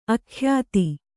♪ akhyāti